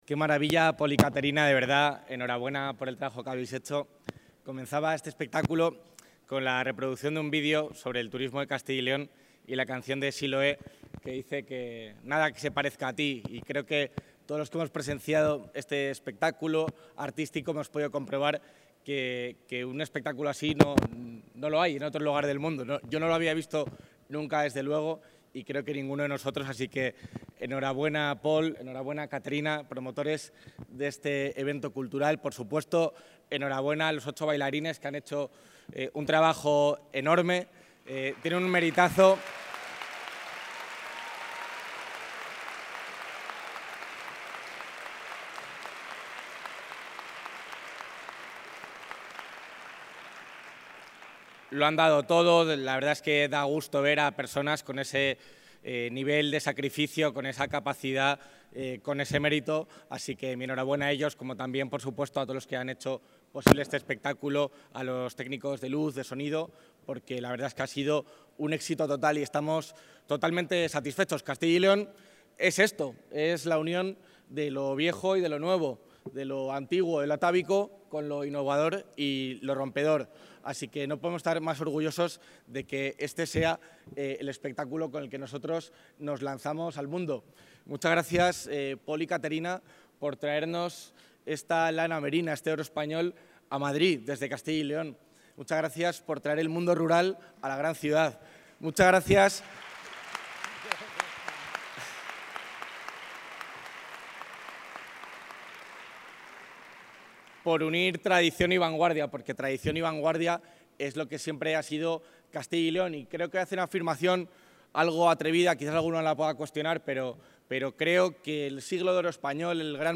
Intervención del vicepresidente de la Junta.
El vicepresidente de la Junta de Castilla y León asiste a la representación del espectáculo ‘Merina, el Oro Español’ en Madrid